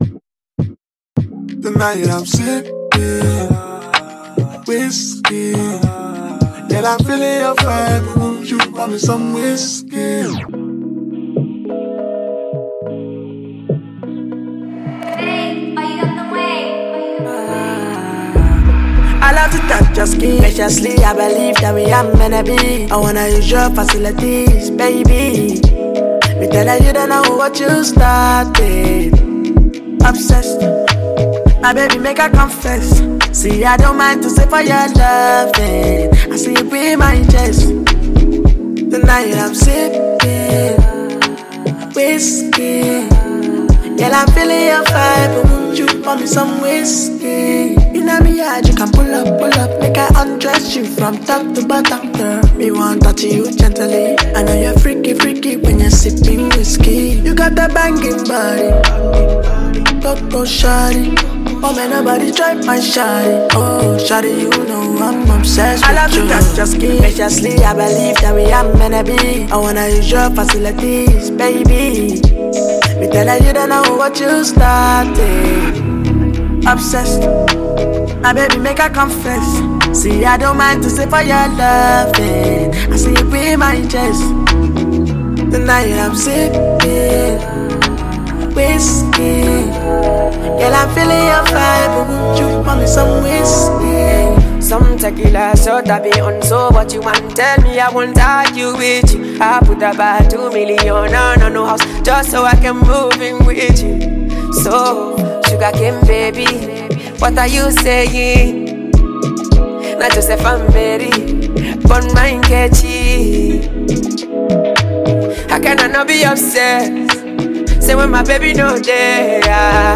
gbedu song